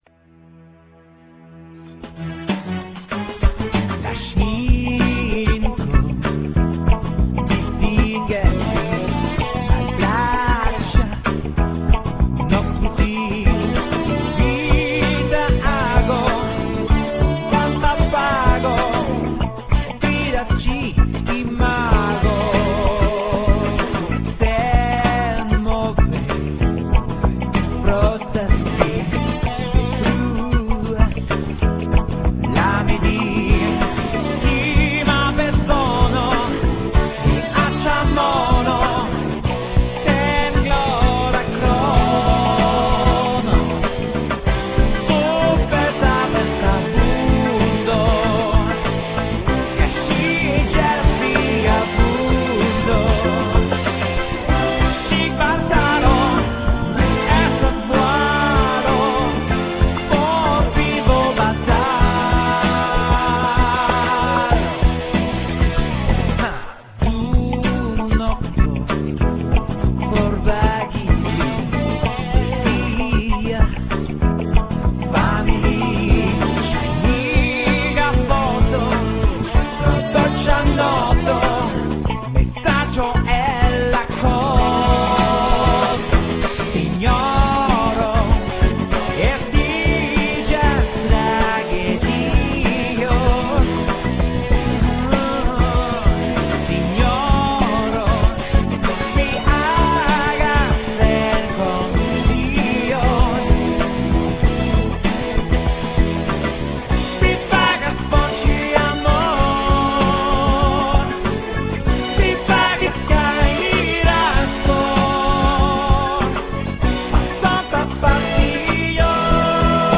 franca gruv-rok-grupo